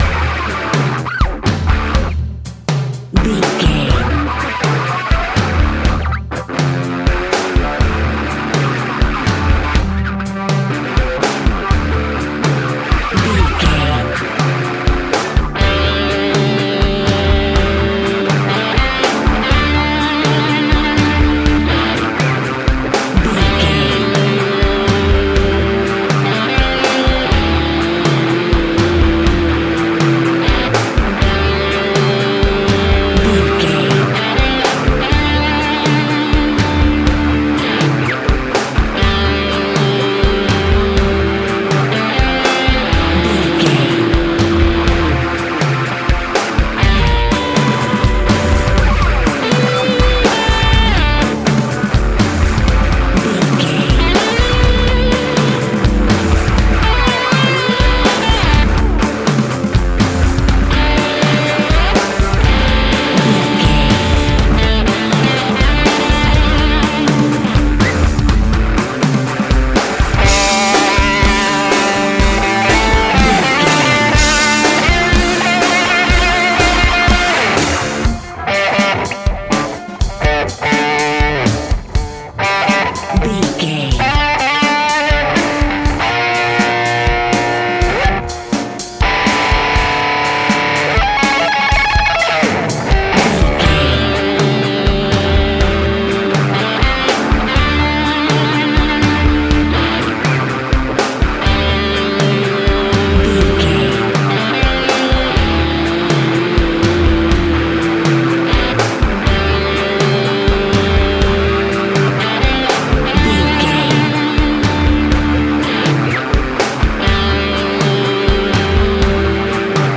Ionian/Major
D
angry
electric guitar
drums
bass guitar